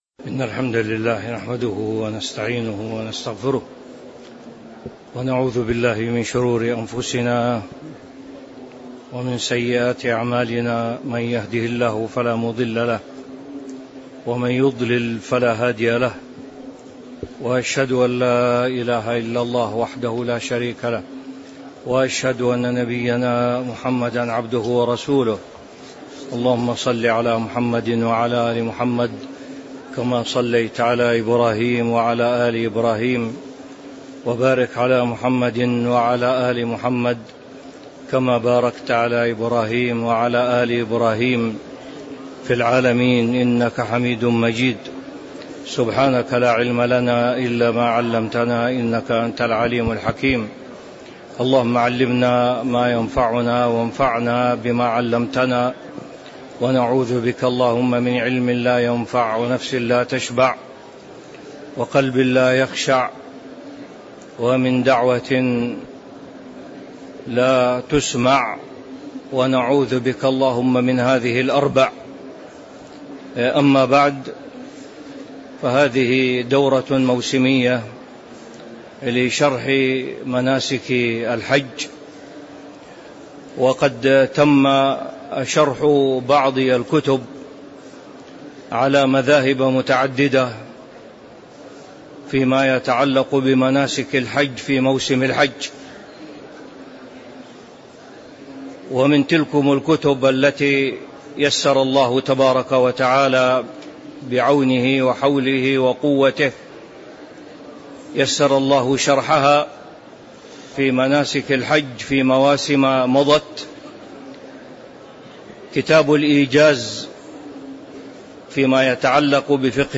تاريخ النشر ١٥ ذو القعدة ١٤٤٦ هـ المكان: المسجد النبوي الشيخ